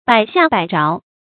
百下百着 bǎi xià bǎi zháo
百下百着发音
成语注音ㄅㄞˇ ㄒㄧㄚˋ ㄅㄞˇ ㄓㄠˊ